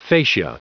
Prononciation du mot facia en anglais (fichier audio)
facia.wav